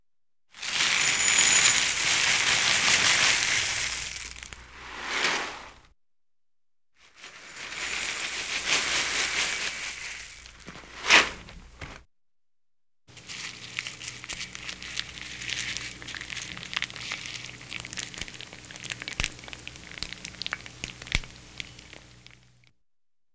household
Cereal Box Pour Into Bowl